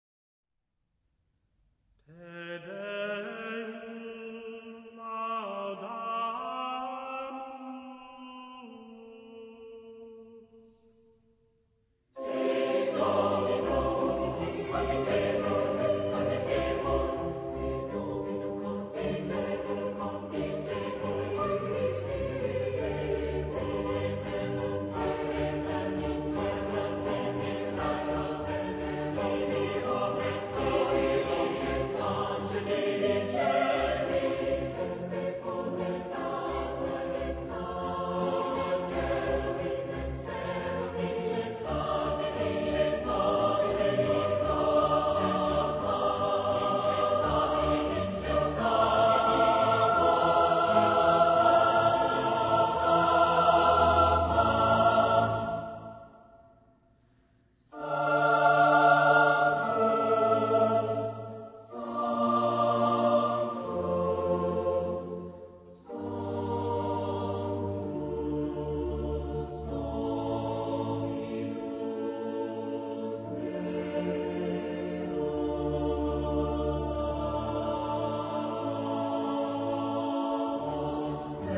Epoque: 18th century
Genre-Style-Form: Sacred ; Baroque
Type of Choir: SATB  (4 mixed voices )